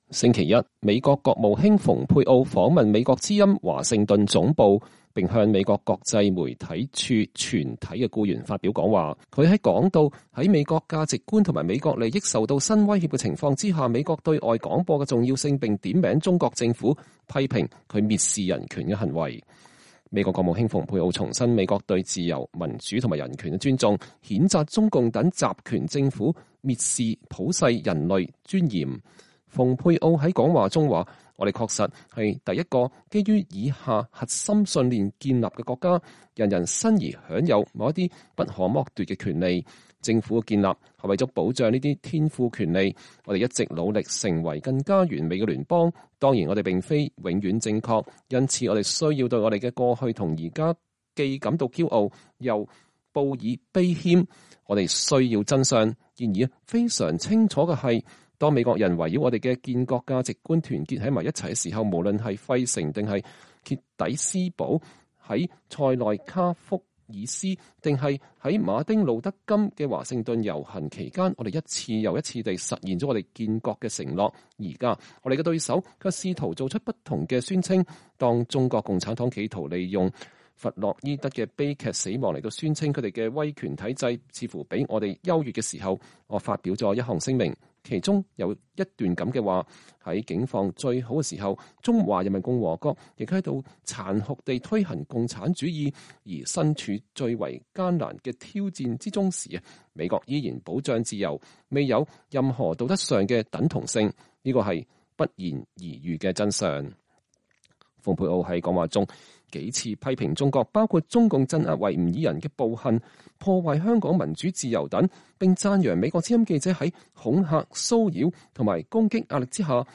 美國國務卿蓬佩奧在美國之音總部發表講話
週一，國務卿蓬佩奧訪問美國之音華盛頓總部，並向美國國際媒體署全體僱員發表講話，他談及在美國價值觀和美國利益受到新威脅的情況下，美國對外廣播的重要性，並點名中國政府，批評其蔑視人權的行為。